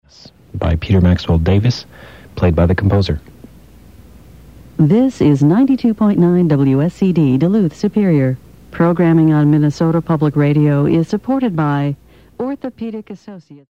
WSCD-FM Top of the Hour Audio: